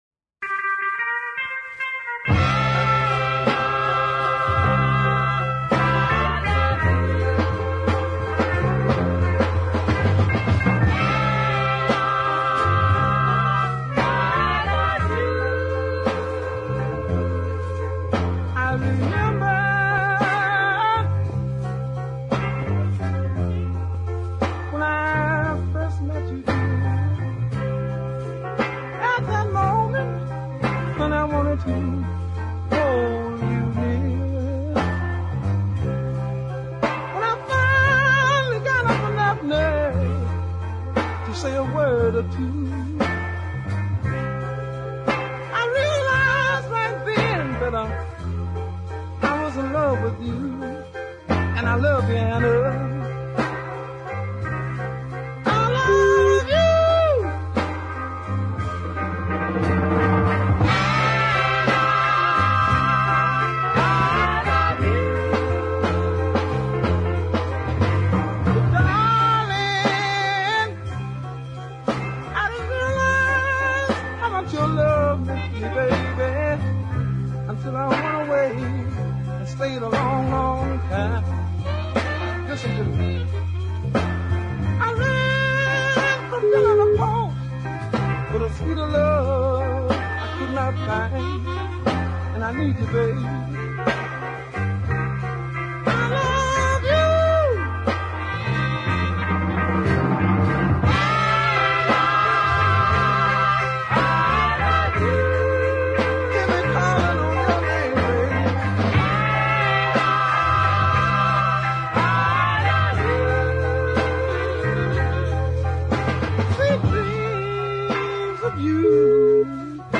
occasional falsetto cries sound just about perfect